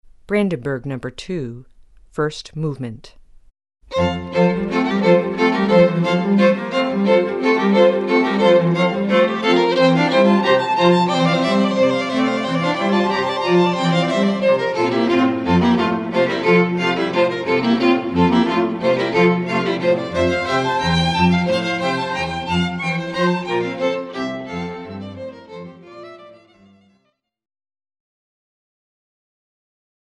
The Music of The Woodvale String Quartet
In addition, we have numerous arrangements, for string quartet and singer, of the most common pieces of music used in the Catholic ceremony and Mass.